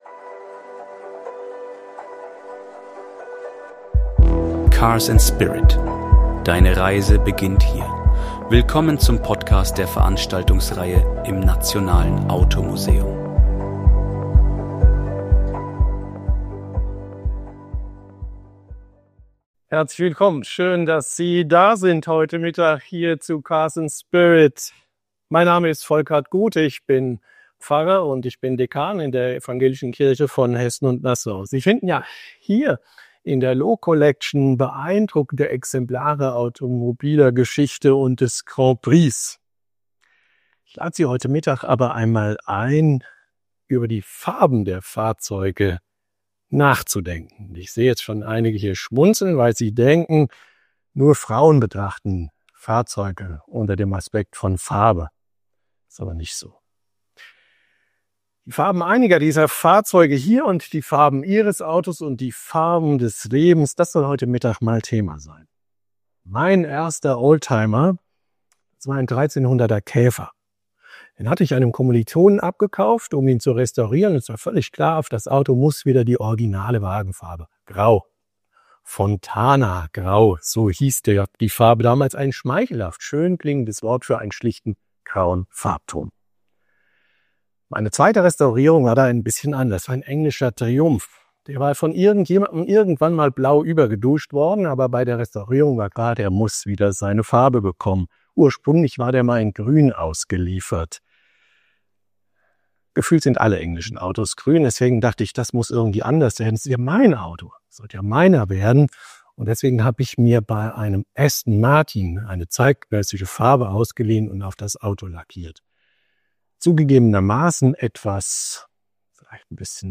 In seinem inspirierenden Vortrag im Nationalen Automuseum